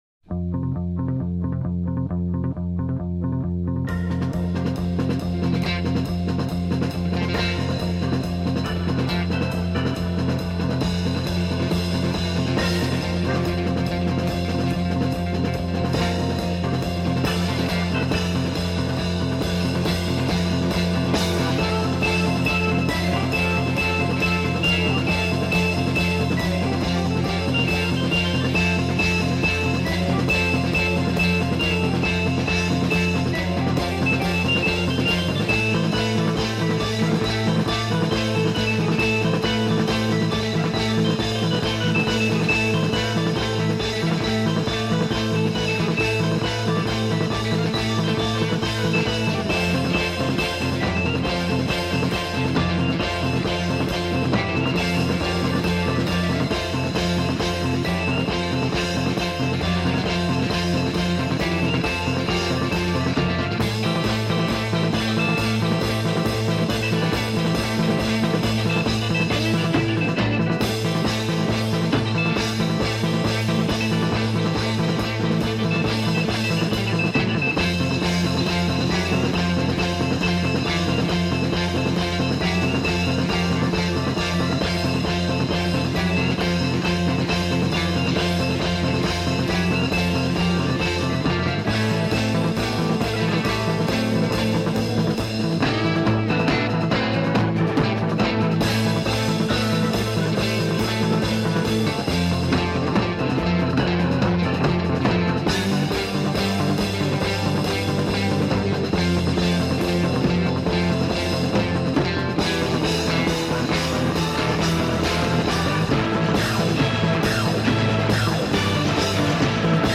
Recorded at Olympic Studios, London in October 1967.
keyboards
guitar
bass
drums
[Tape splice at transition point]
Art Rock